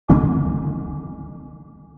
click-heavy.mp3